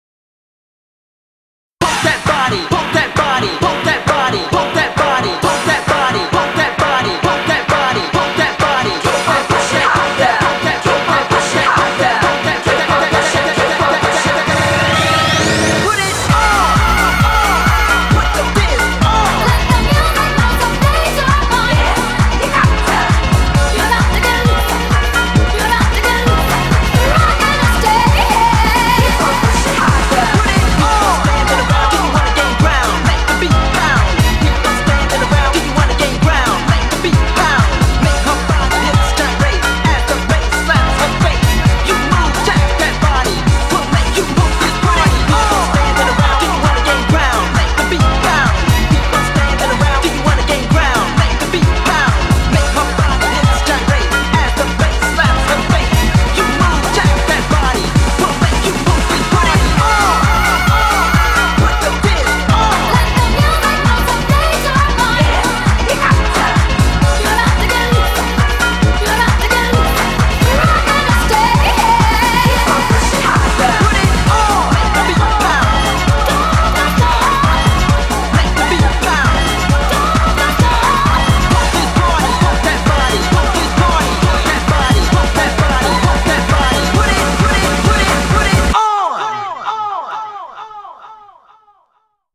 BPM132
Audio QualityMusic Cut
No audio exists so a custom cut was made.